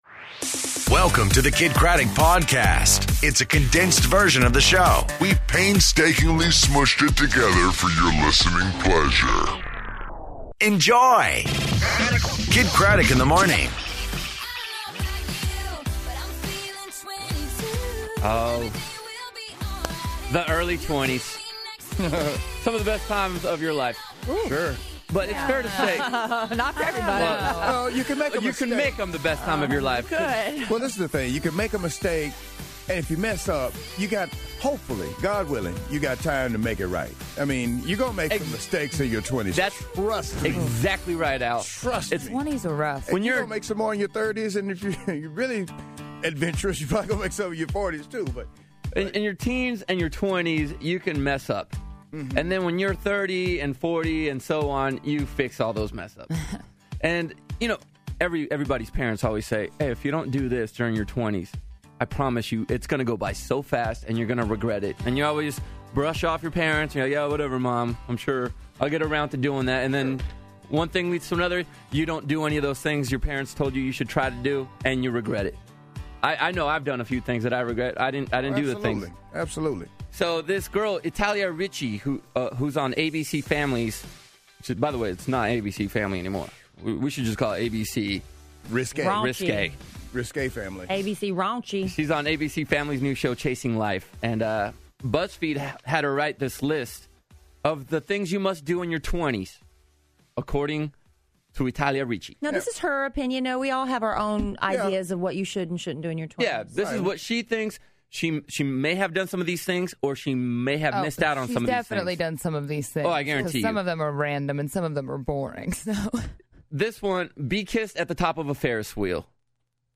I Have A Dream, Things To Do In Your Twitter, And Anjelah Johnson In Studio